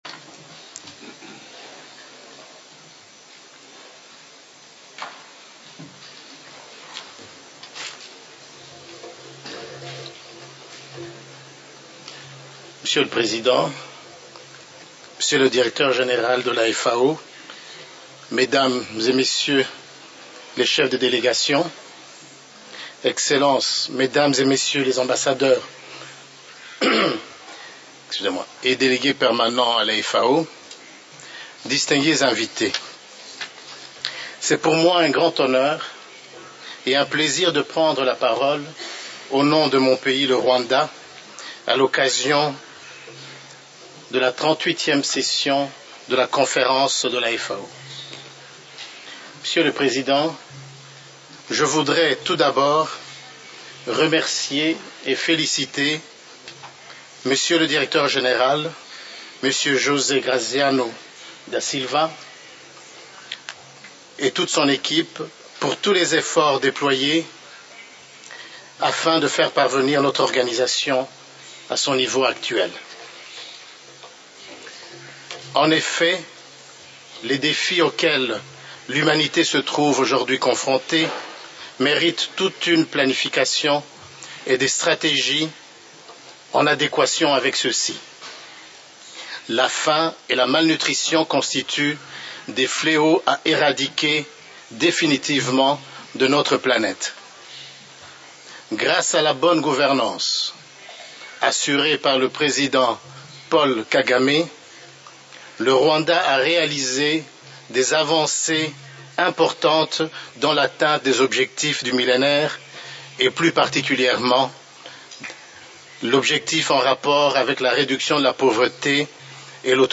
FAO Conference
Statements by Heads of Delegations under Item 9:
Son Excellence Monsieur Jacques Kabale Nyangezi Ambassadeur, Représentant permanent du Rwanda auprès de la FAO